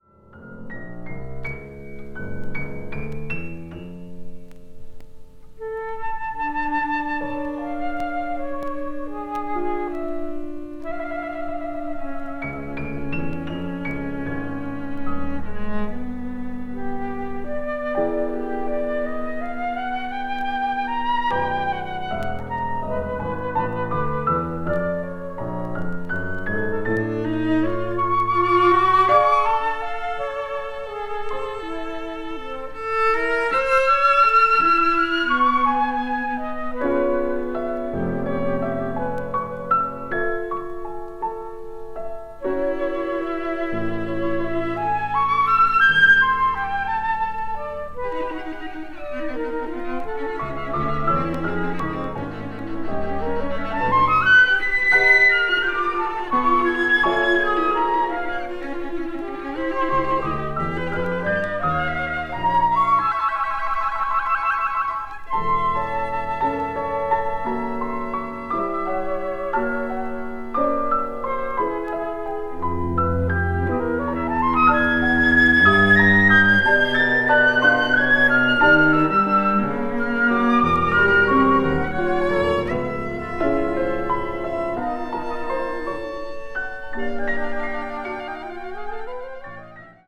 media : EX-/EX-(some light noises.)